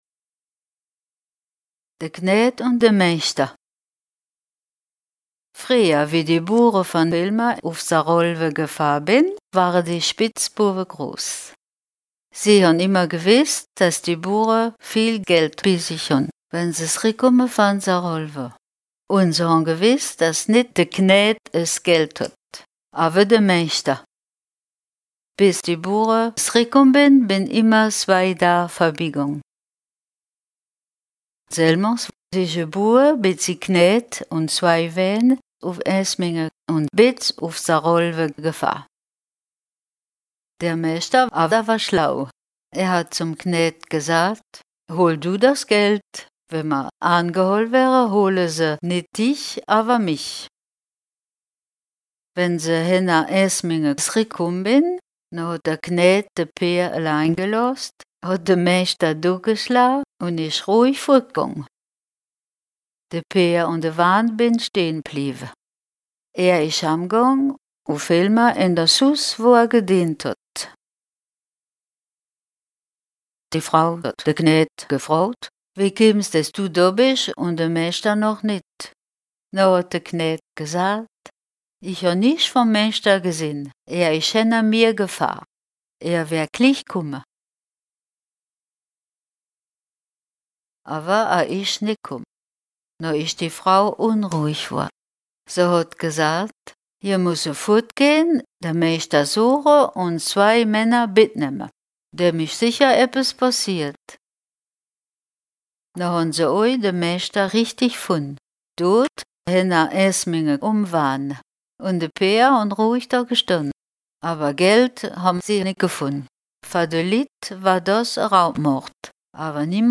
Contes et récits en ditsch enregistrés dans les communes de Linstroff, Grostenquin, Bistroff, Erstroff, Gréning, Freybouse, Petit Tenquin-Encheville, Petit Tenquin et Hellimer-Grostenquin.